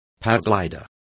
Complete with pronunciation of the translation of paraglider.